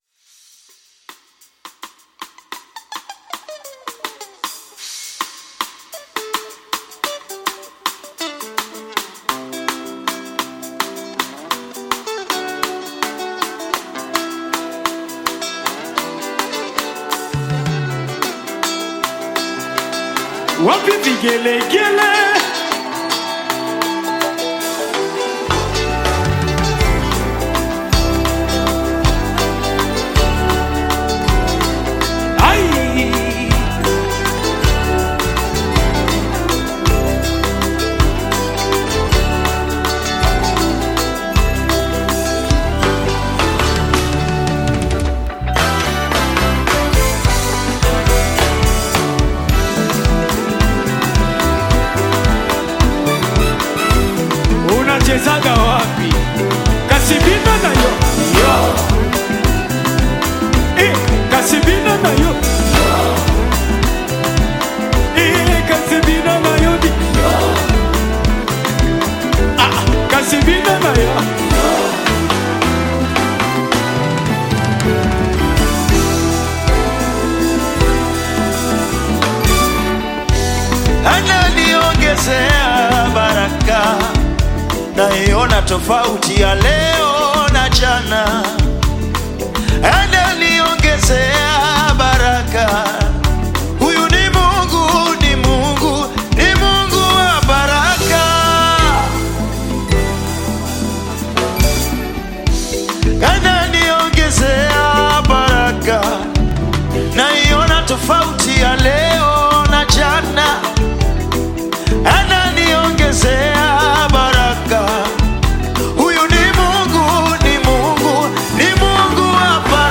Nyimbo za Dini music
Gospel music track